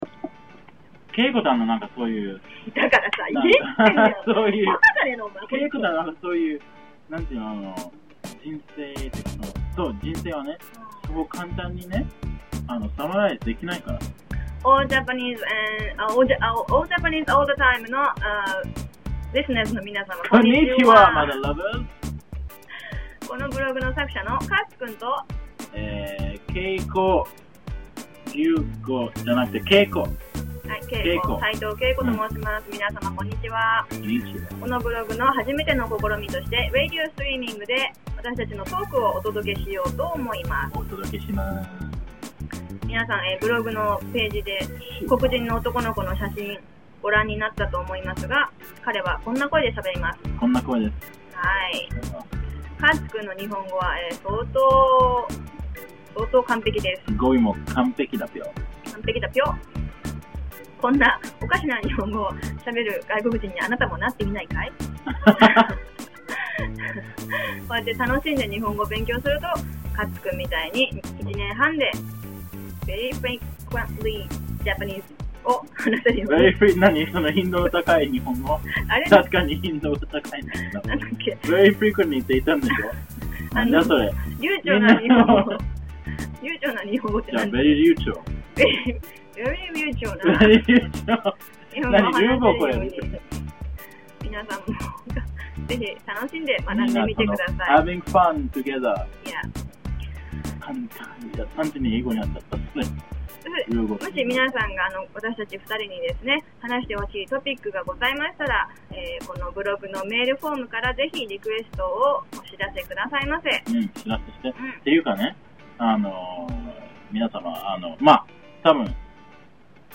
Well, at this stage, it’s less podcasting and more just talking.
You need a better mic, or to up the bitrate or something, but the speech was very difficult to hear. The music sounded perfect, so it’s probably a mic issue.